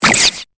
Cri de Statitik dans Pokémon Épée et Bouclier.